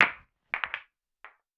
Ball Impact Multiple.wav